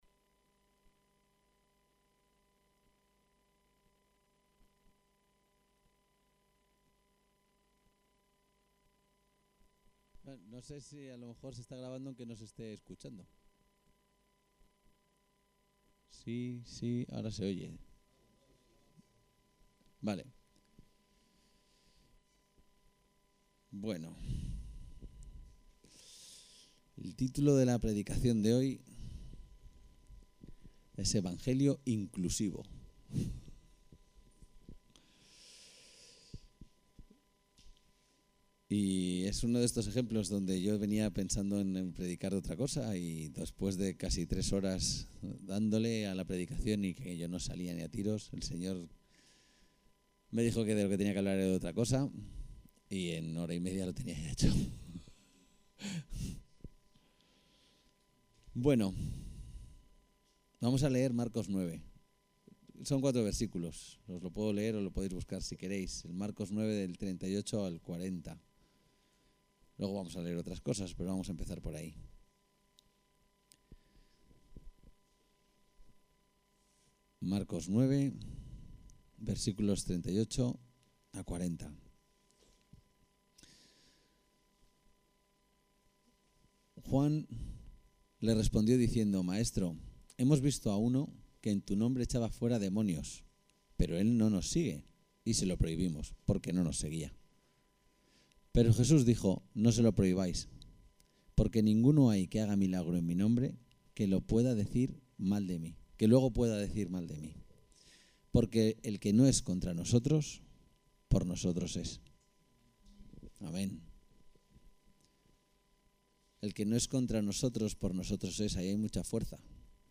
El texto de la predicación se puede descargar aquí=> Pruebas en la fe